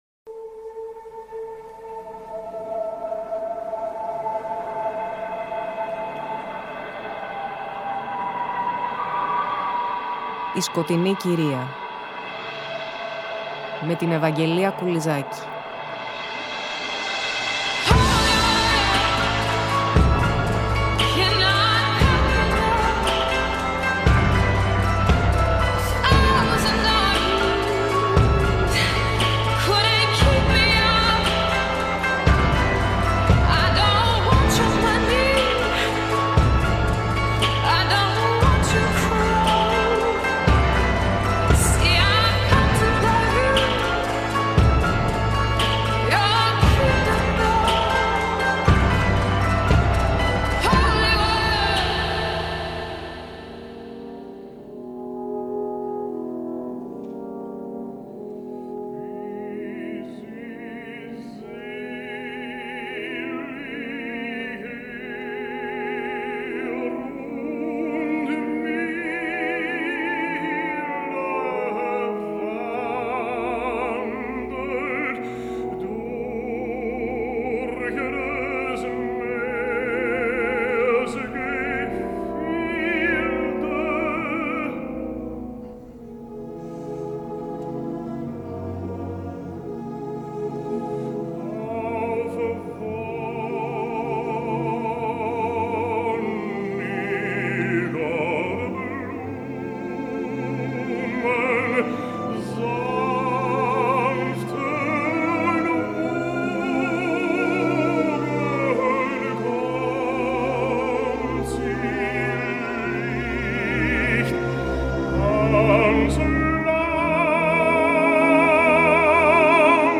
Η μουσική πλαισίωση παραμένει βαγκνερική, καθώς ακούμε αποσπάσματα από την όπερα ”Τριστάνος και Ιζόλδη”.